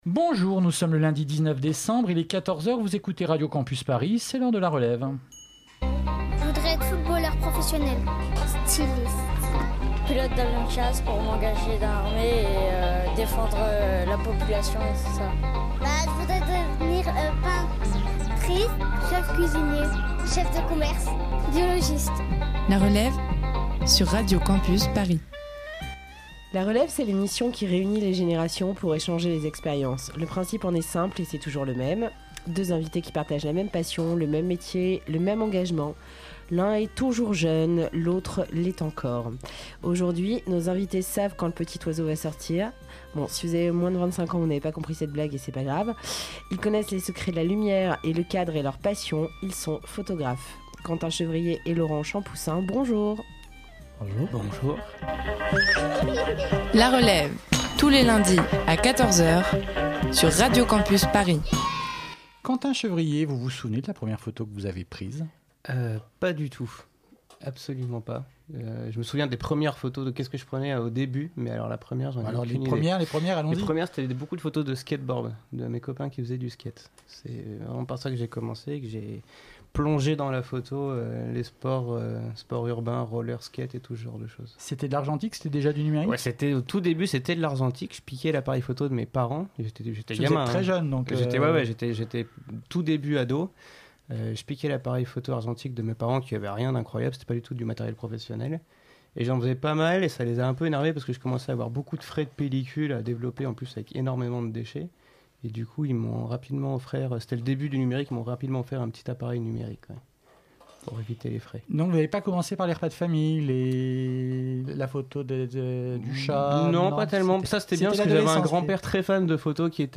Aujourd'hui, nous allons vous faire rencontrer deux photographes, deux hommes dont la passion est de fixer l'image, en numérique ou pas, ce qu'ils aiment c'est regarder et leur regard est leur pouvoir.